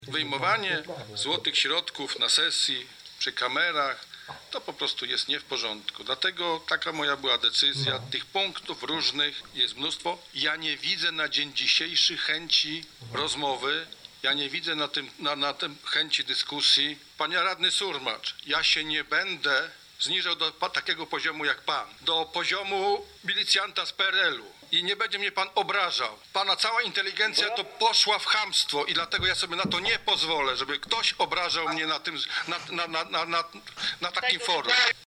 Do gorących zajść doszło podczas dzisiejszej sesji sejmiku lubuskiego.
Podczas dyskusji na temat Medyka głos zabrał przewodniczący sejmiku Wacław Maciuszonek, który tłumaczył dlaczego nie zgodził się na wprowadzenie punktu dotyczącego dyskusji na temat przyszłości zielonogórskiego szpitala, o co wnioskowali radni Samorządowego Lubuskiego. W pewnym momencie Maciuszonkowi puściły nerwy i nazwał radnego Marka Surmacza milicjantem z PRL: